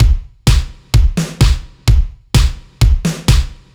Index of /musicradar/french-house-chillout-samples/128bpm/Beats
FHC_BeatC_128-03_KickSnare.wav